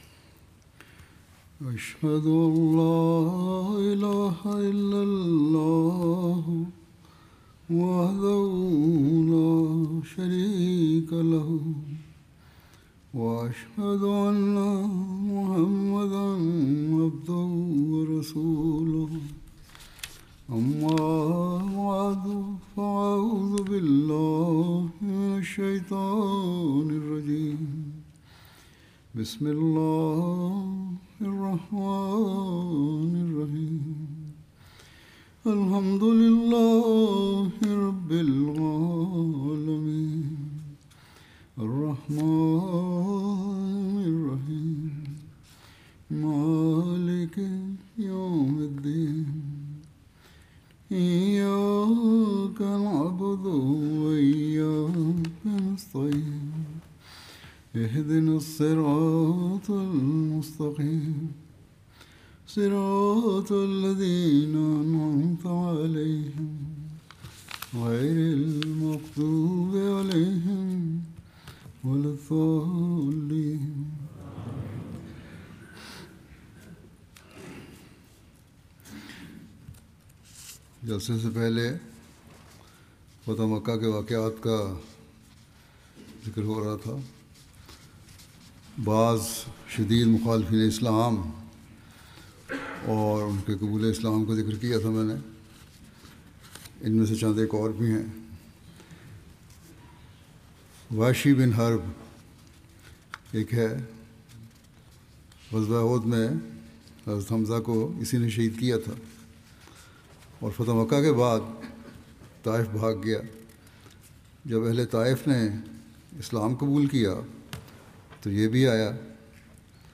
Urdu Friday Sermon by Head of Ahmadiyya Muslim Community
Urdu Friday Sermon delivered by Khalifatul Masih